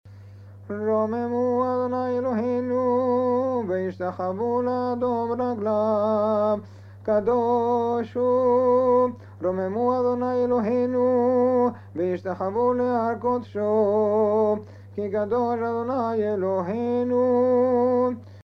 Spanish-Portuguese Tunes
[Western Sephardic]